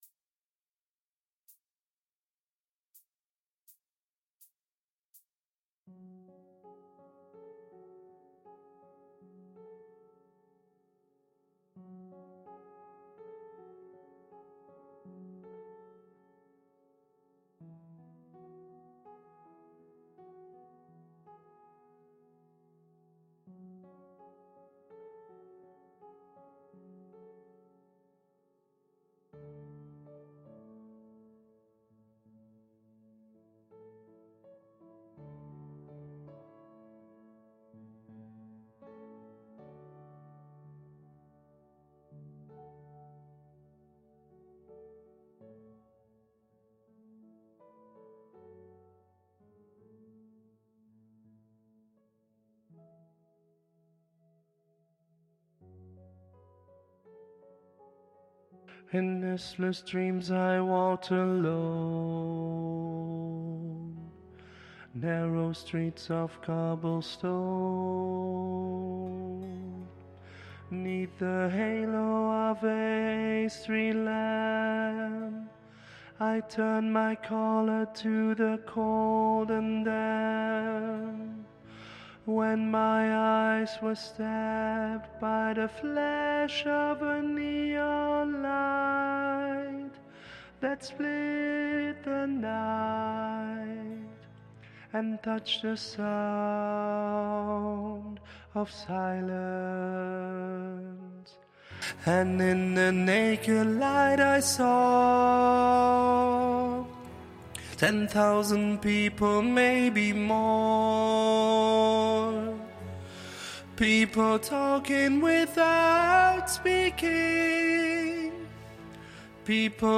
Sound Of Silence SOPRAN